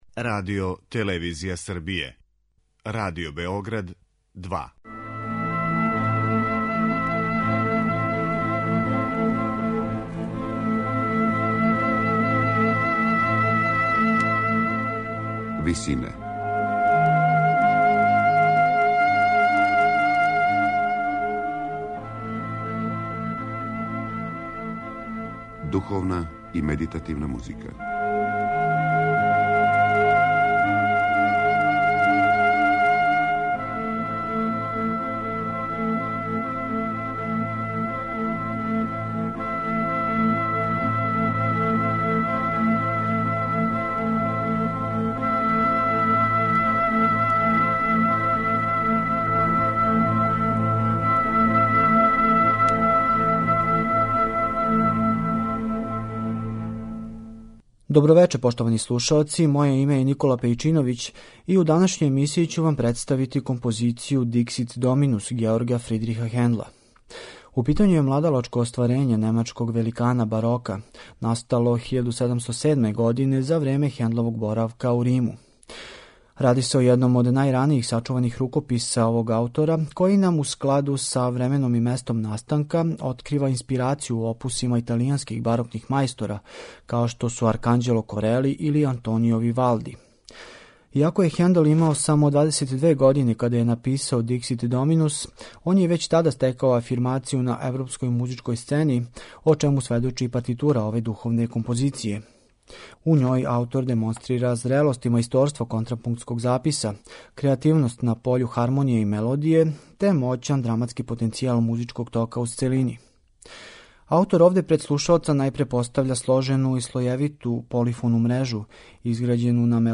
Хендл у овом духовном вокално-инструменталном делу као текстуални предложак користи Псалм 110.
барокни ансамбл
медитативне и духовне композиције